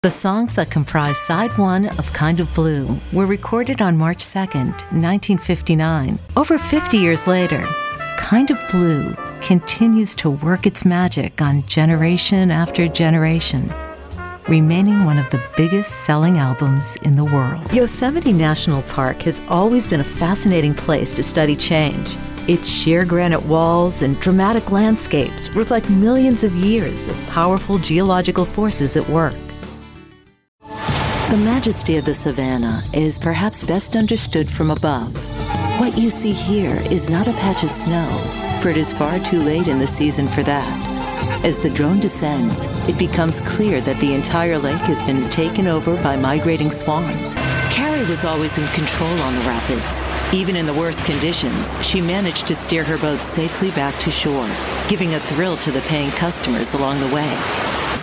TV Shows
With a warm grounded (North American English) voice, wry smile and a storyteller’s heart, I want people to feel something real when they listen.
Acoustic Paneled and Sound Treated- 12'x6' recording studio and workstation